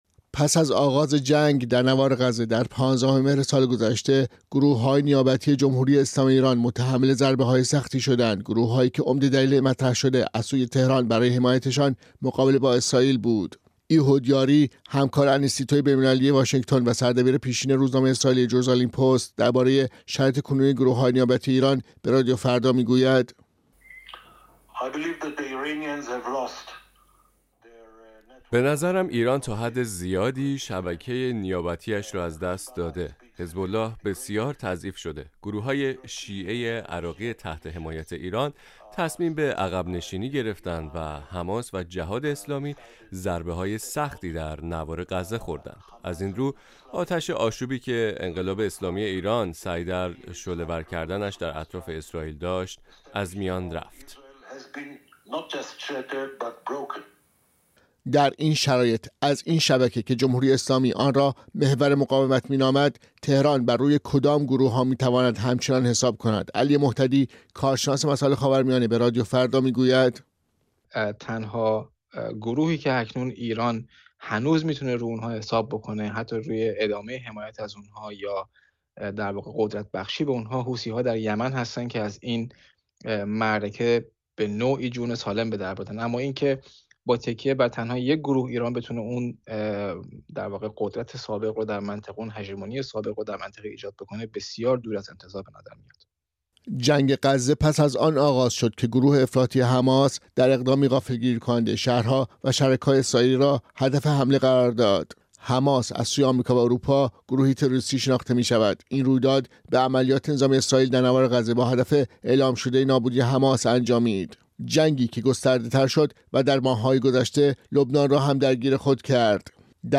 این توافق، چه تاثیری بر جمهوری اسلامی ایران و نفوذ منطقه‌ای‌اش خواهد داشت؟ گزارشی